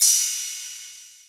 DY 4500 Hat.wav